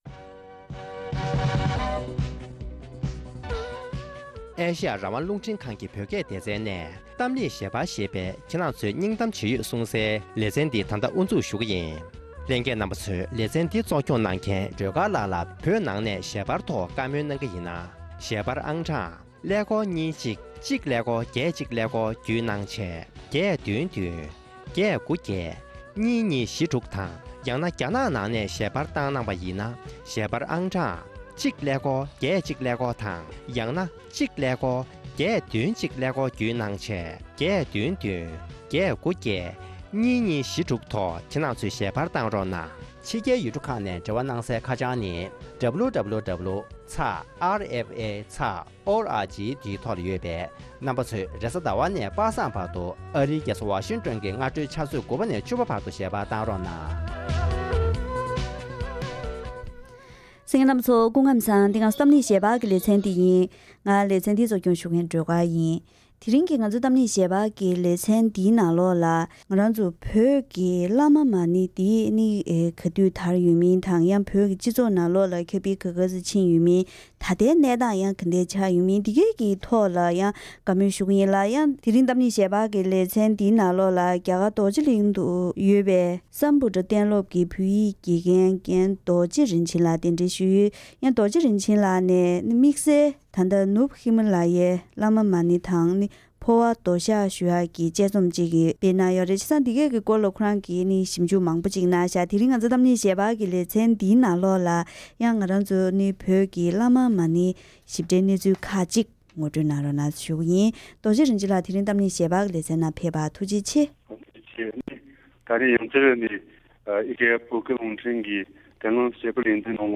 ༄༅། །དེ་རིང་གི་གཏམ་གླེང་ཞལ་པར་ལེ་ཚན་ནང་། བོད་ཀྱི་བླ་མ་མ་ཎི་གསུང་སྲོལ་གྱི་རིག་གཞུང་ཇི་ལྟར་དར་ཚུལ་དང་། བོད་ཀྱི་སྤྱི་ཚོགས་ཐོག་གི་ཤུགས་རྐྱེན། ད་ལྟ་བླ་མ་མ་ཎི་རིག་གཞུང་འདི་བཞིན་སྟོང་ལ་ཉེ་བའི་ཉེན་ཁ་ཡོད་པའི་སྐོར་སོགས་གནས་ཚུལ་ཁག་གི་ཐད་འབྲེལ་ཡོད་དང་བཀའ་མོལ་ཞུས་པ་ཞིག་གསན་རོགས་གནང་།།